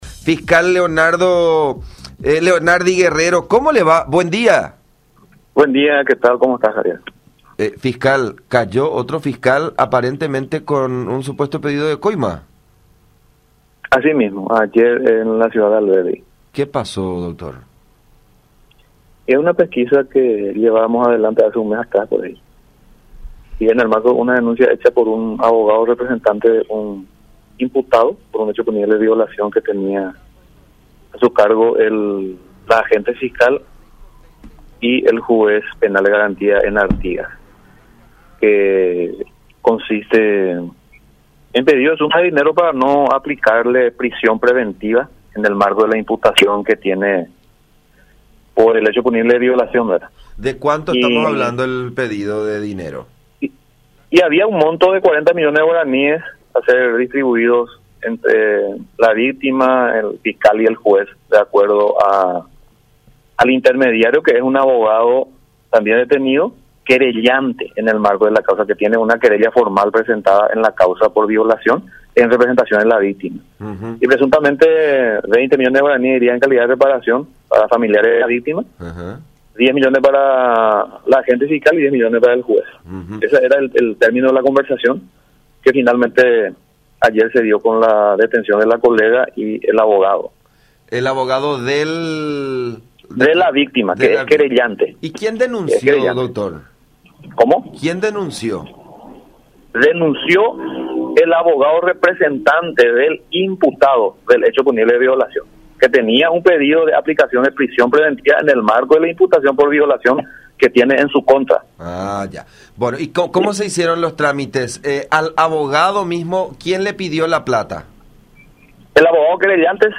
06-Dr.-Leonardi-Guerrero-Agente-Fiscal-sobre-fiscal-y-abogado-detenidos-por-supuesta-coima.mp3